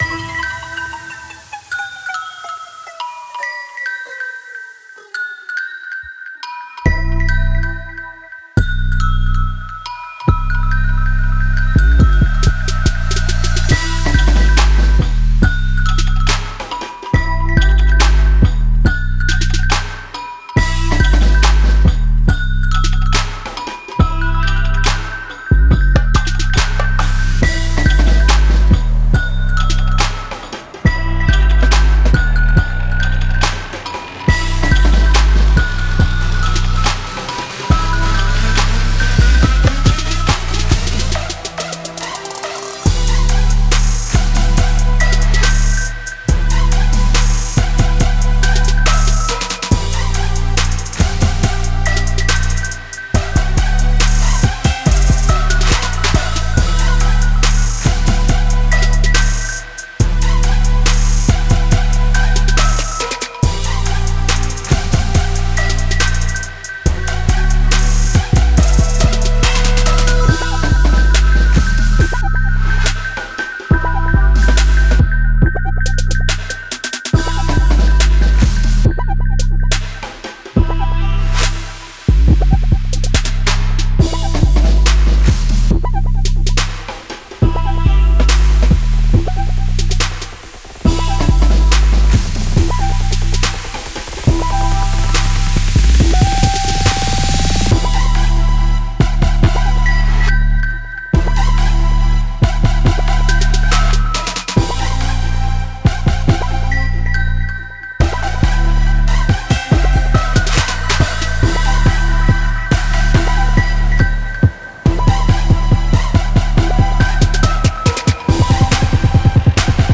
trap минус для поругания
Пробую силы в создание минусовки в стиле trap. Сводил восновном в наушиках - без читкии не считаю нужным сильно заморачиваться, но всеж получилось весьма недурно, на мой взгляд...